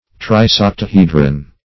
Trisoctahedron - definition of Trisoctahedron - synonyms, pronunciation, spelling from Free Dictionary
Trisoctahedron \Tris*oc`ta*he"dron\, n. [Gr.